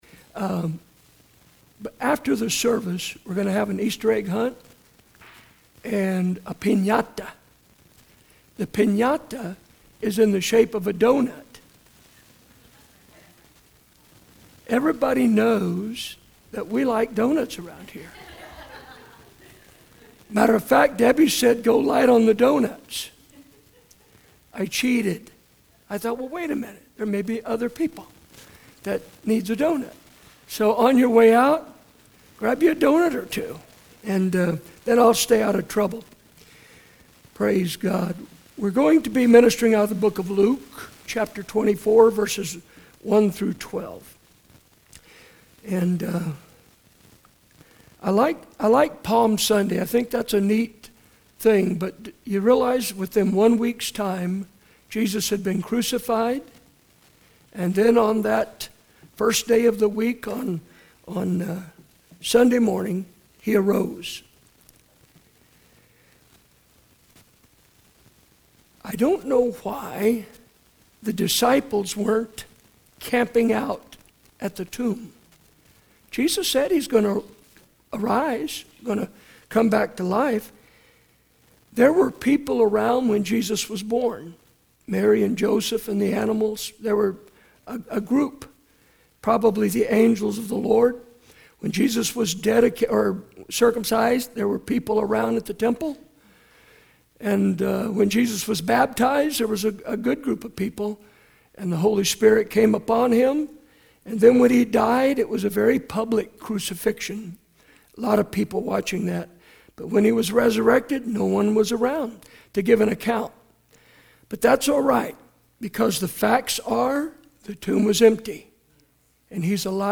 Easter Sunday Service
Morning Sermons